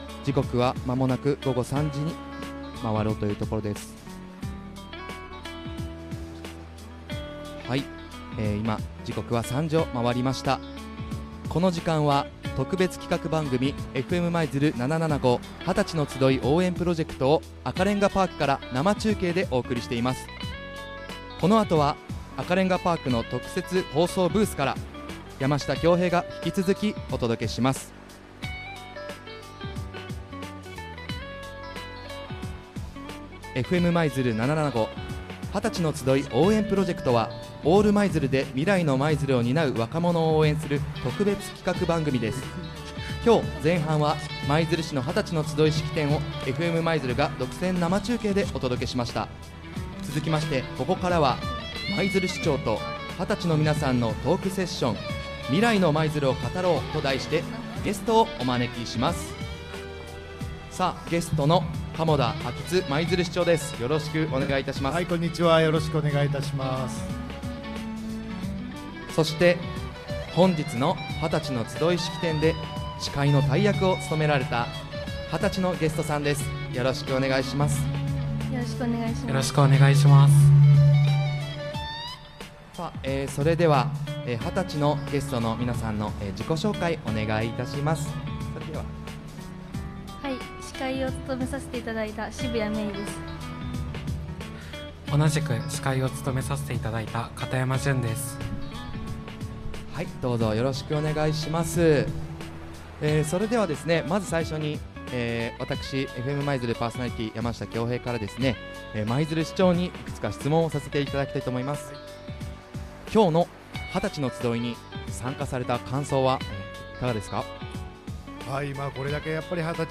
放送時間：令和7年１月12日 13:30～15:30（赤れんがパーク５号棟から）